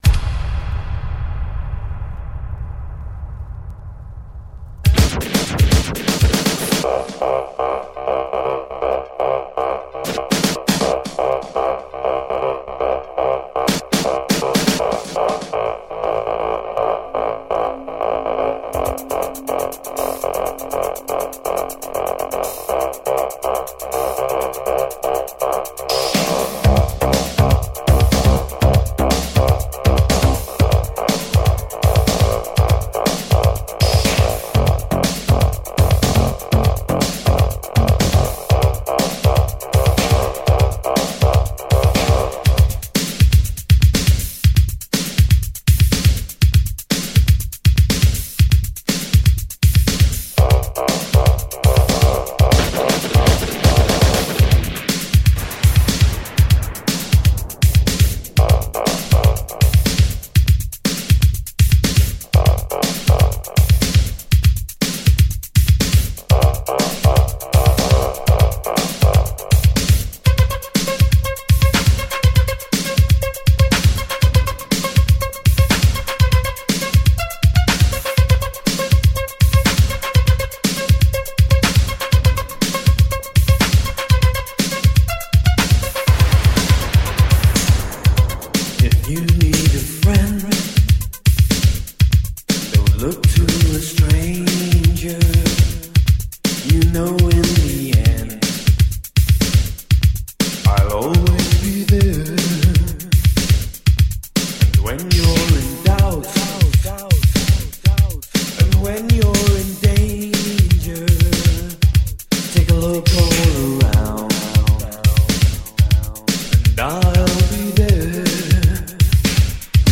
Типа Indie Pop, New Wave.